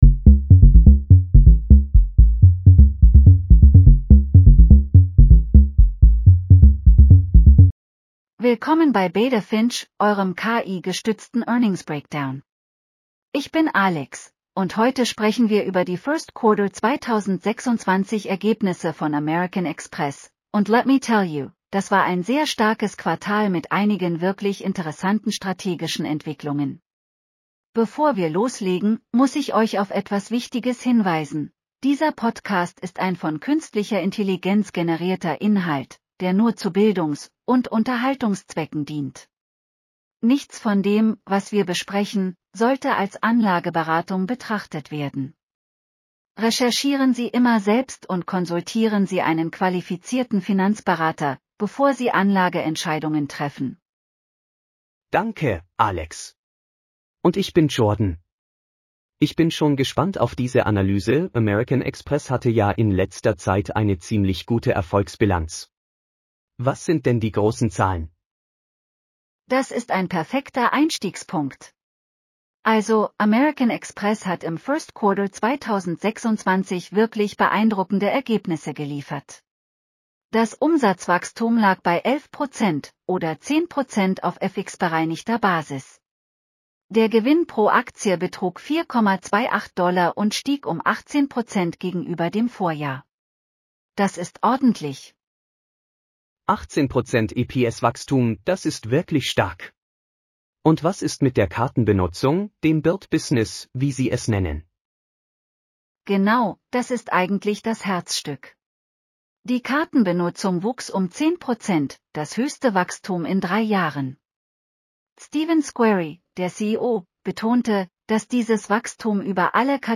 American Express Q1 2026 earnings call breakdown.